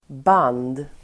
Uttal: [ban:d]